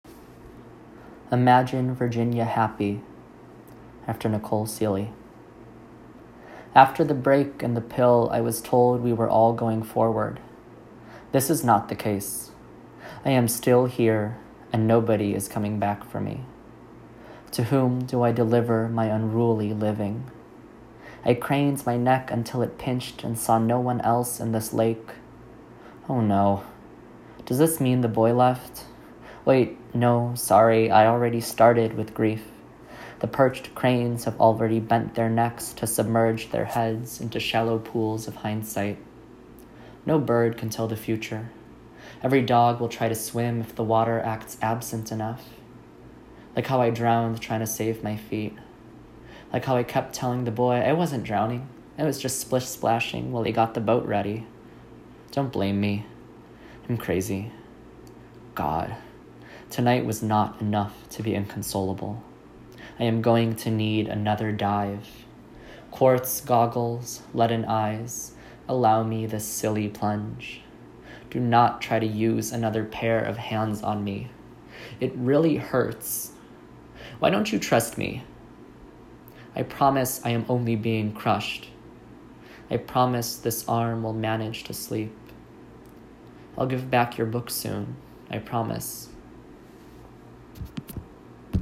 Click to hear this poem out loud.